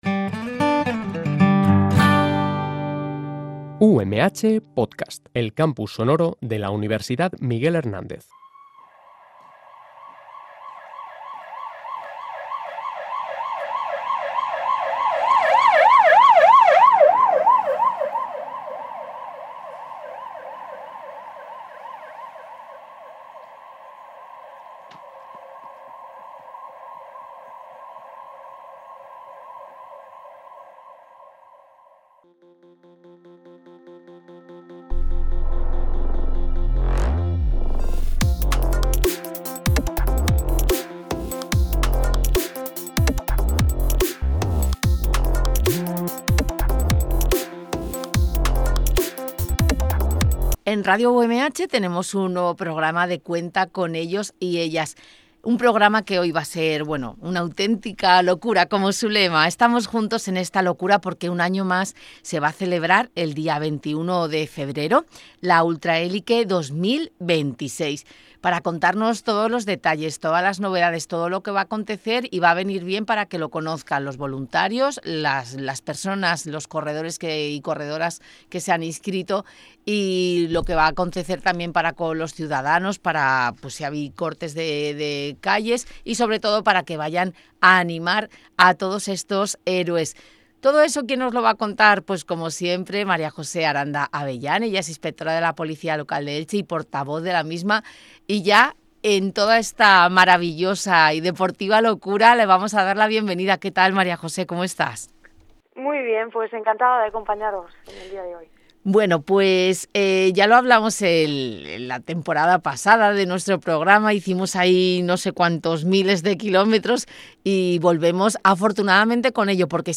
En Radio UMH emitimos una temporada más el espacio radiofónico «Cuenta con ellos y ellas», un programa en colaboración con la Policía Local de Elche en el que vamos a tratar muchos aspectos relacionados con las tareas que realizan en su día a día y temas de muchísimo interés para la ciudadanía.